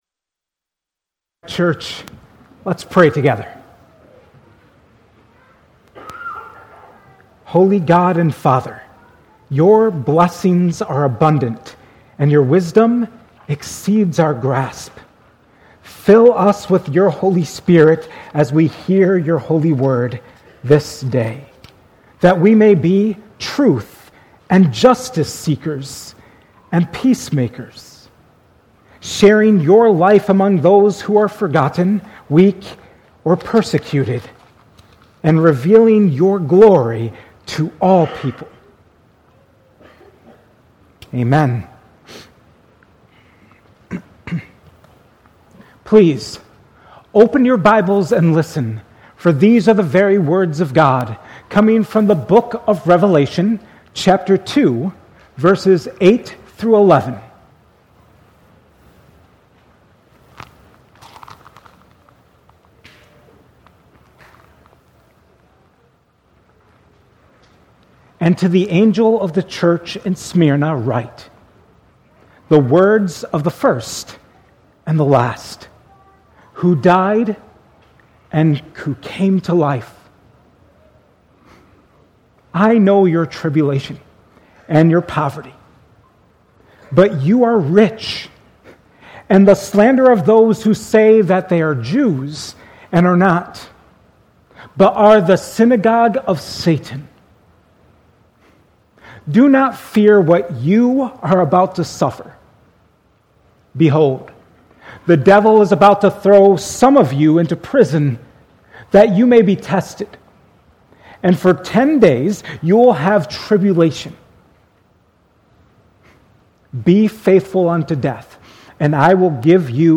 2026 at Cornerstone Church in Pella.